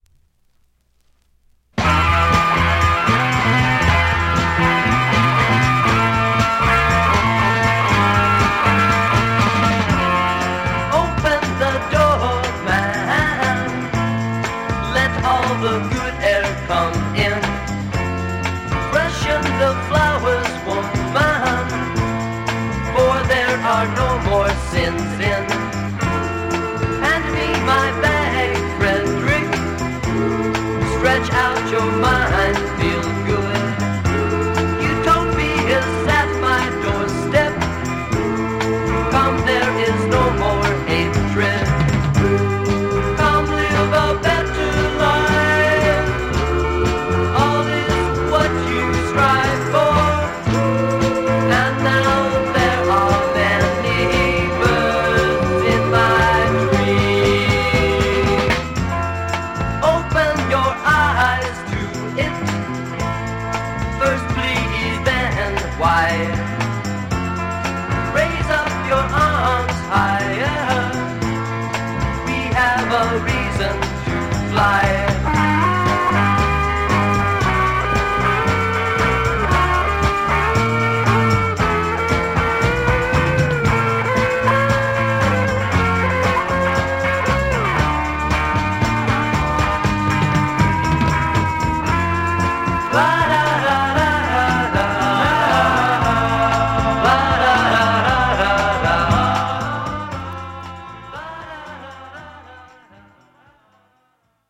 US West coast psych
Classic US garage psych west cost single.